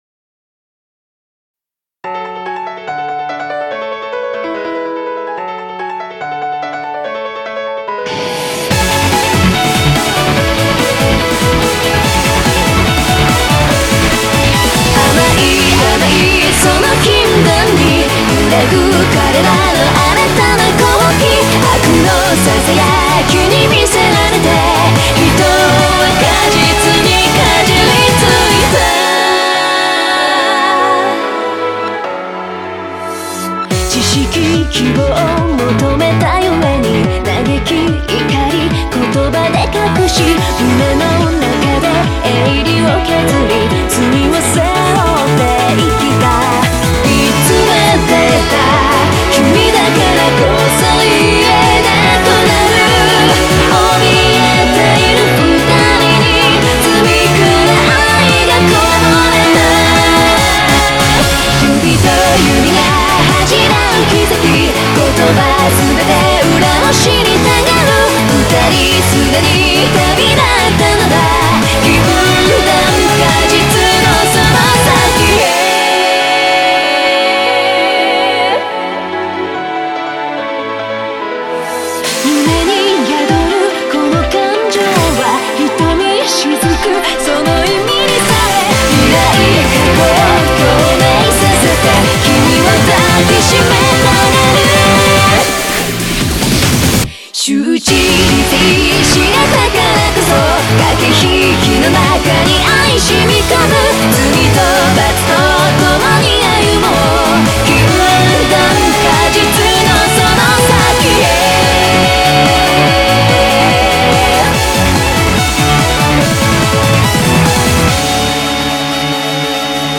BPM144
Audio QualityPerfect (High Quality)
コメント[HYPER J-POP]